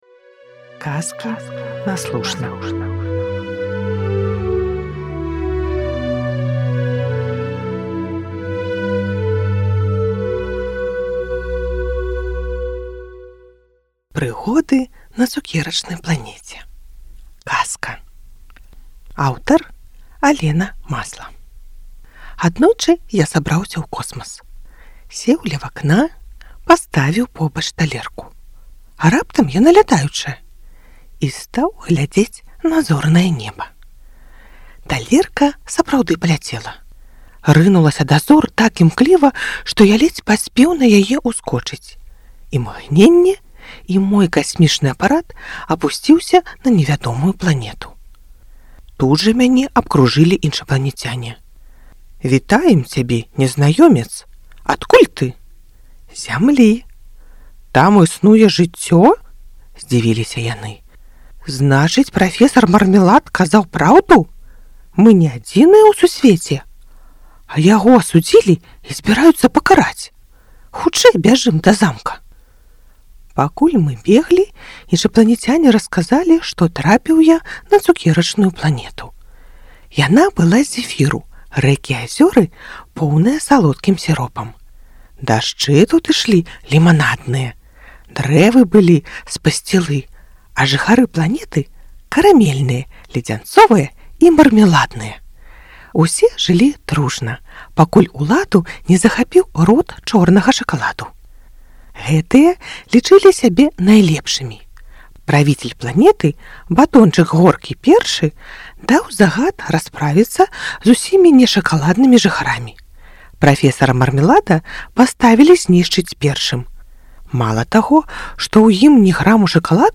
Прыгоды на цукерачнай планеце (казка) + аўдыё